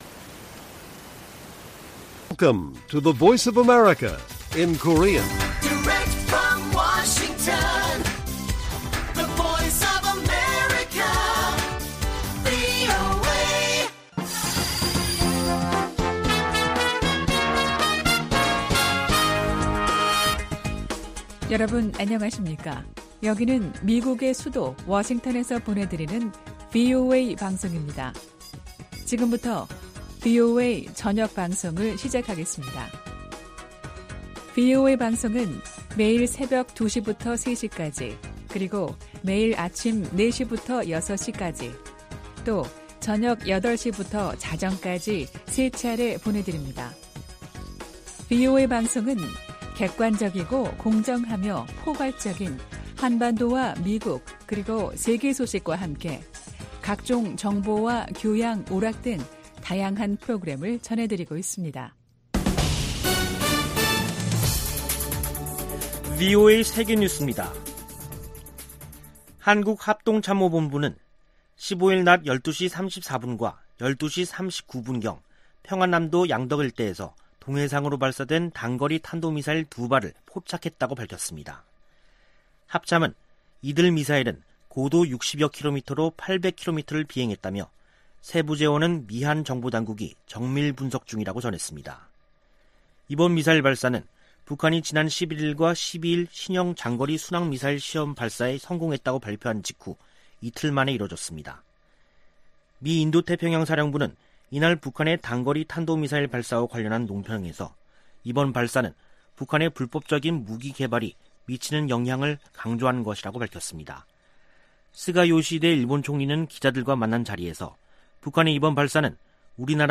VOA 한국어 간판 뉴스 프로그램 '뉴스 투데이', 2021년 9월 15일 1부 방송입니다. 북한이 15일 동해로 단거리 탄도미사일 두 발을 발사했습니다.